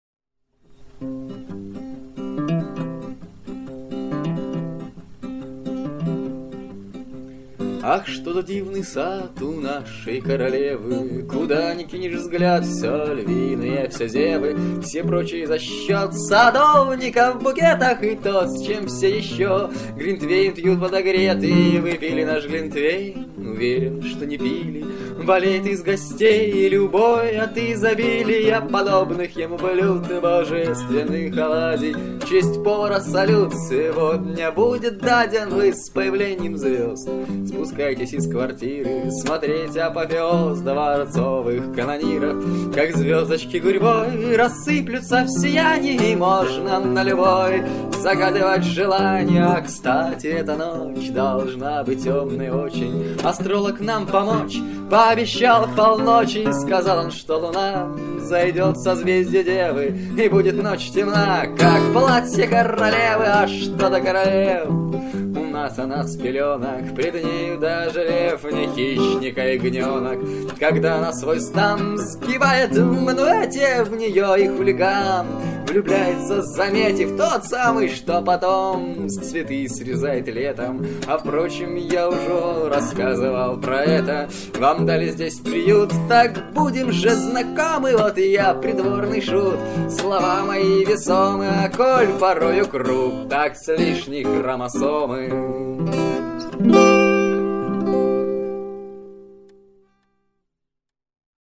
На одной старой кассете в мамином архиве нашёлся целый комплект всякого добра из 1995 года.